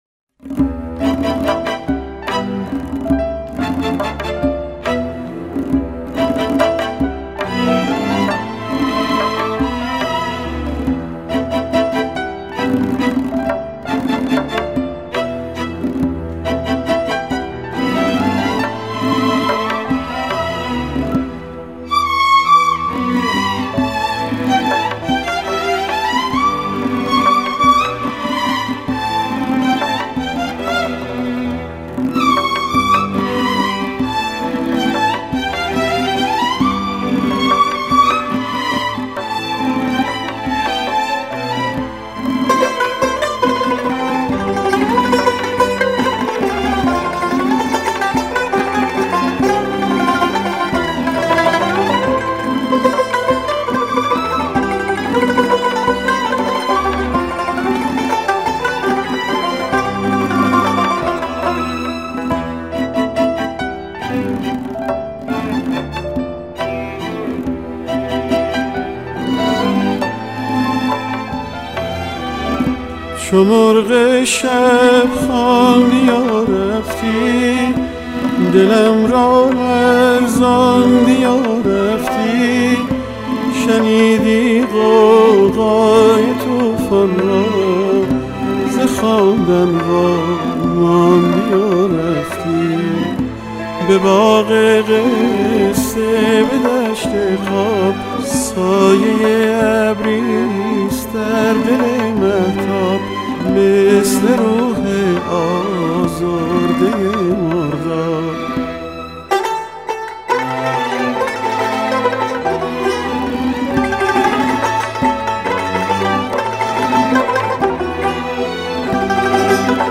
سنتور
کمانچه